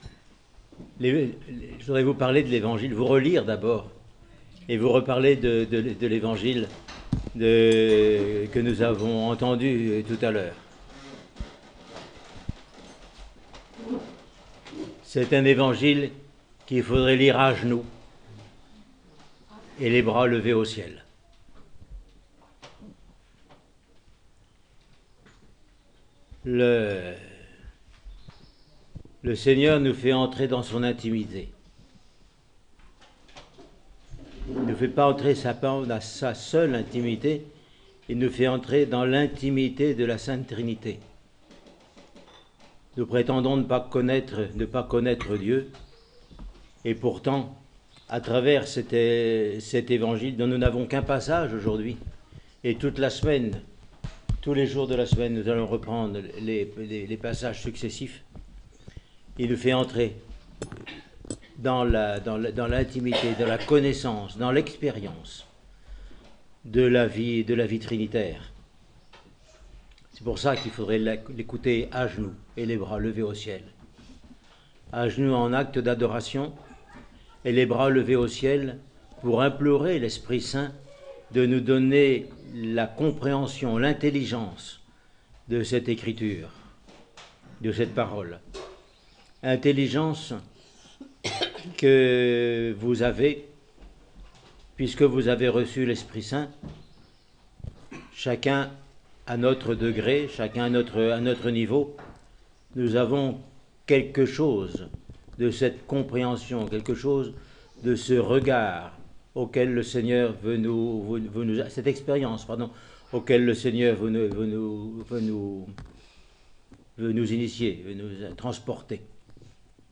Homélie sur l’évangile du dimanche des Saints Pères du premier concile :Monastère de la Transfiguration